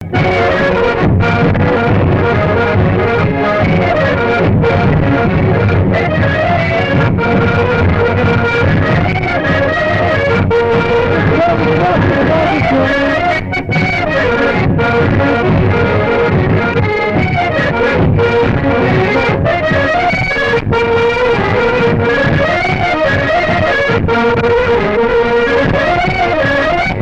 Beauvoir-sur-Mer
danse : angoise, maristingo
Genre brève
Pièce musicale inédite